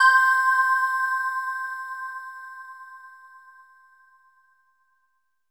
LEAD C5.wav